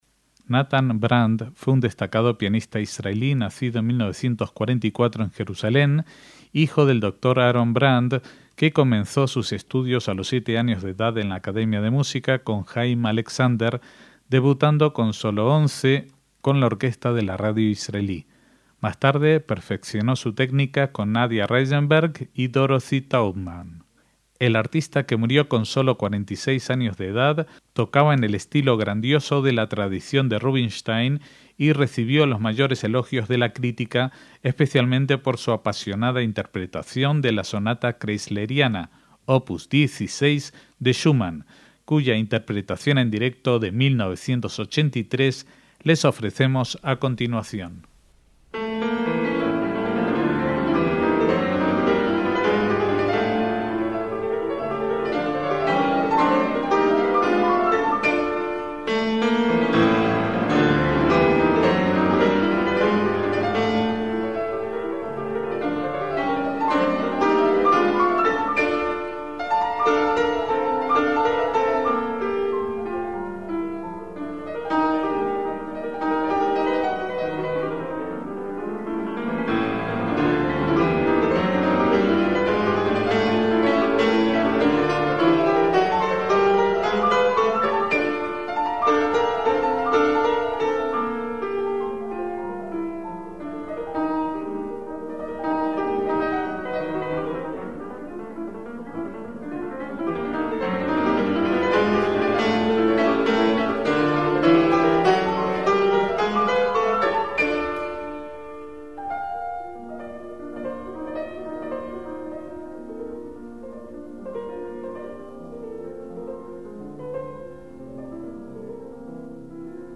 MÚSICA CLÁSICA
Lo escuchamos en la interpretación en directo en 1983 de la obra Kreisleriana de Robert Schumann, siendo su